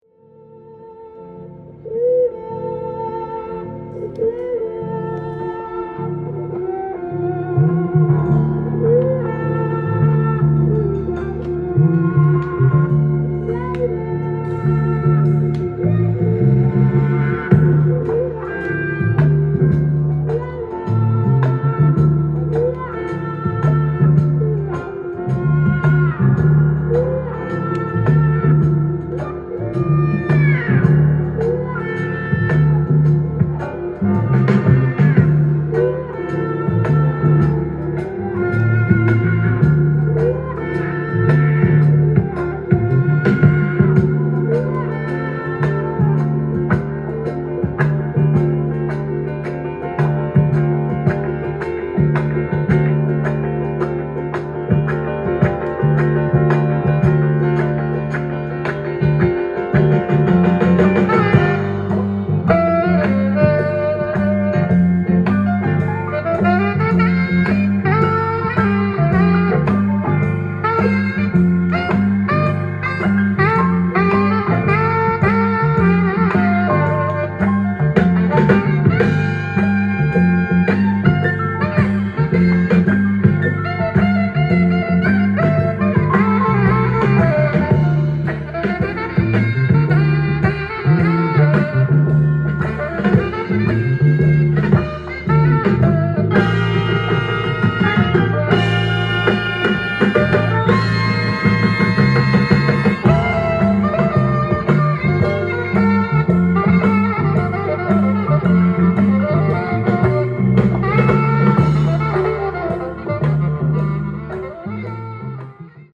ジャンル：FUNK
店頭で録音した音源の為、多少の外部音や音質の悪さはございますが、サンプルとしてご視聴ください。